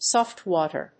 音節sòft wáter